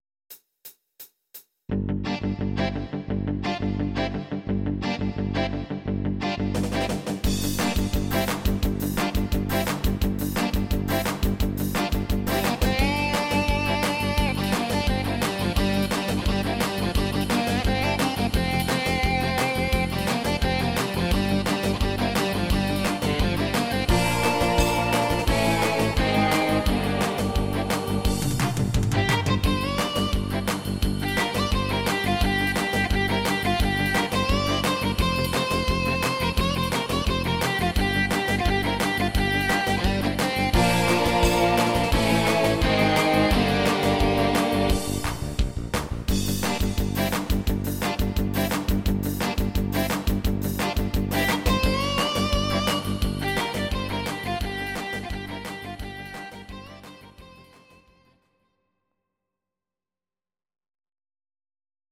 instr. Gitarre